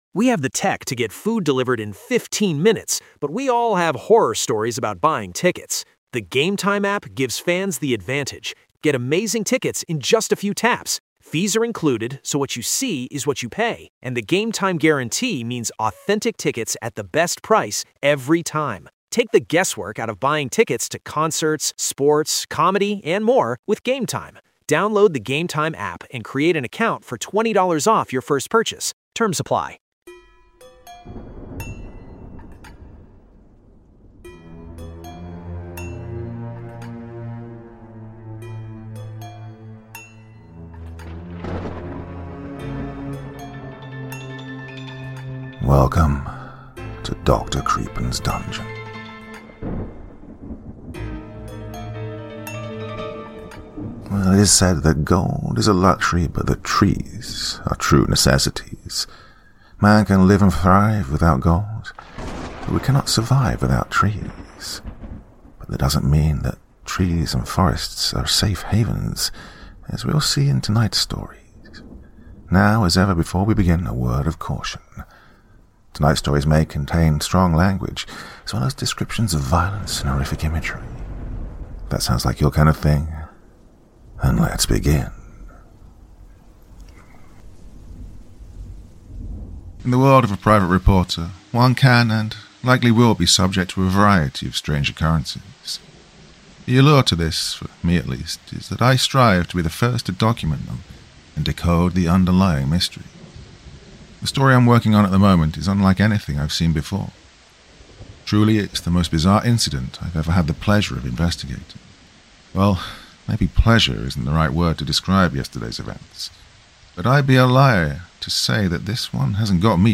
narrated here for you all